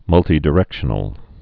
(mŭltē-dĭ-rĕkshə-nəl, -dī-, -tī-)